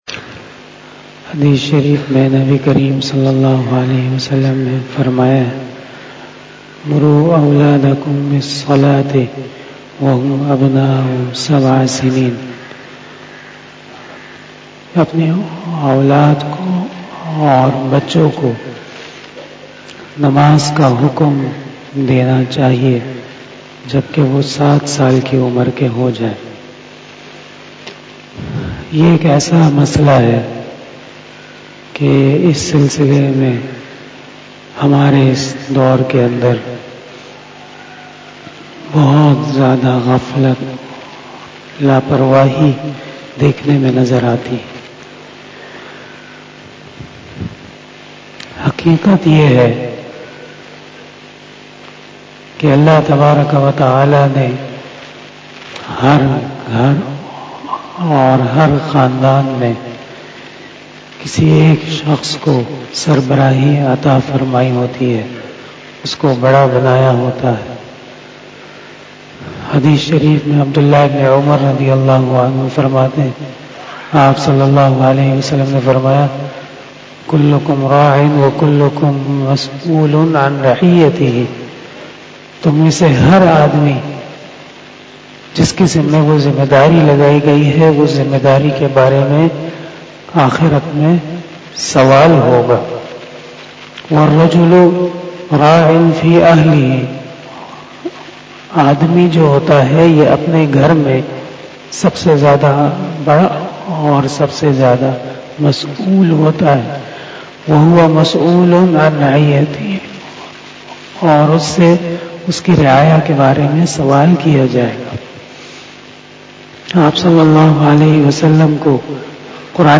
004 After Fajar Namaz Bayan 12 January 2021 ( 27 Jamadil Uola 1442HJ) Tuesday